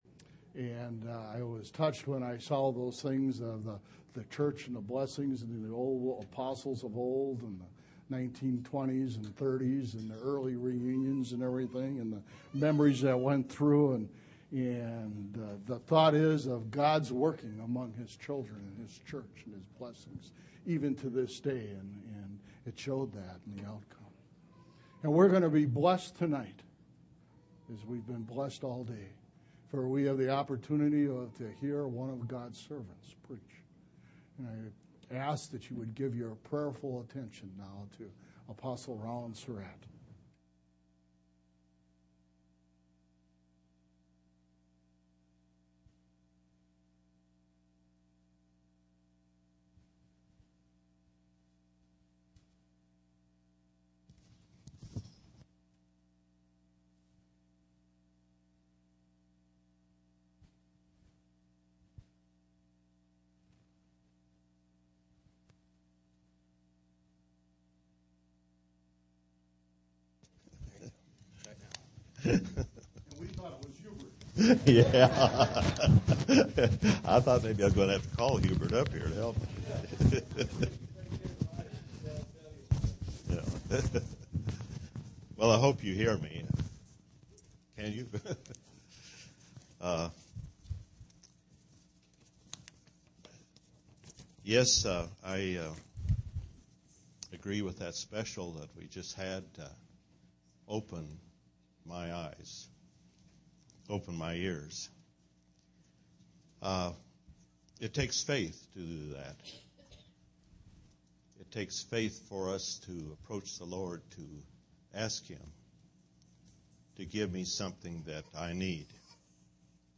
11/28/2008 Location: Phoenix Reunion Event: Phoenix Reunion